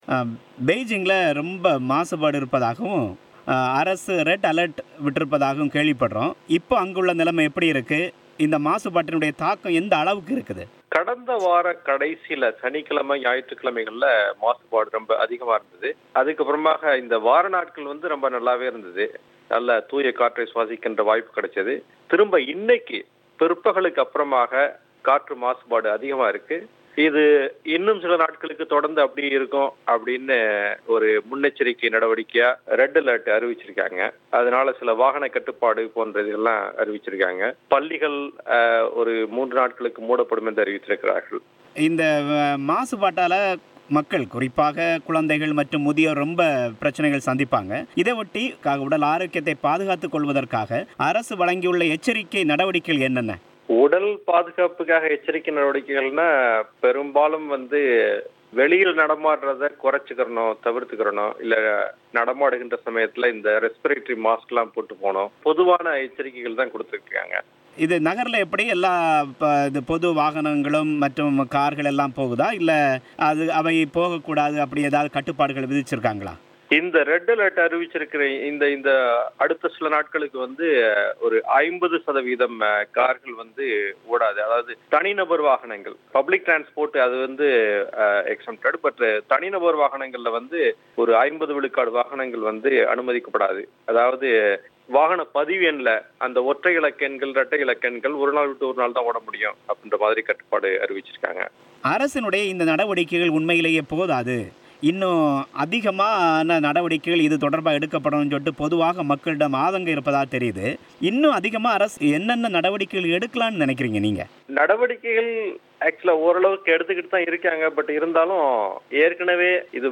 சீனாவில் உள்ள அதிகரிக்கும் நச்சுக்காற்று குறித்த பேட்டி